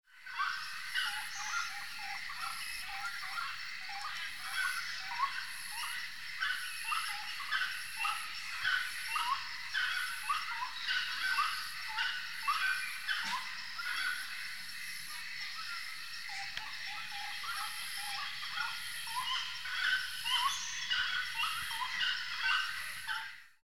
Chiricote (Aramides cajaneus)
Nombre en inglés: Grey-cowled Wood Rail
Localidad o área protegida: Delta del Paraná
Condición: Silvestre
Certeza: Vocalización Grabada
chiricote.mp3